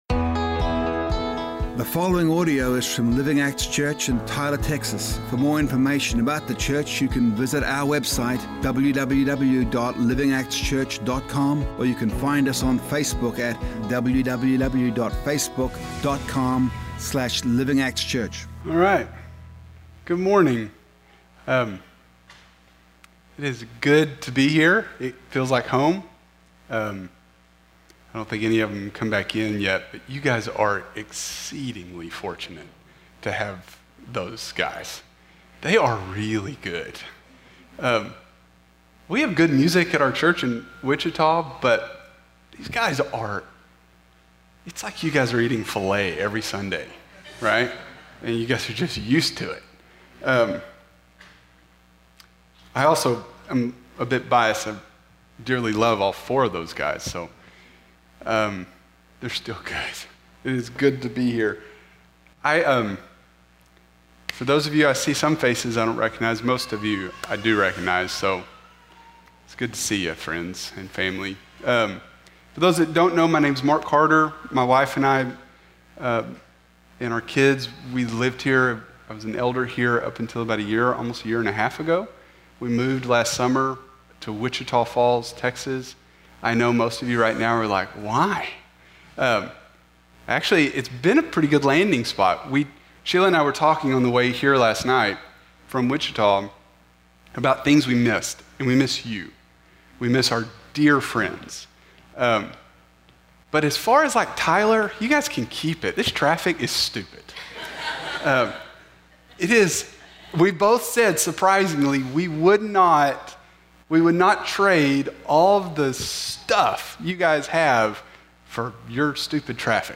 A message from the series "The Parables of Jesus."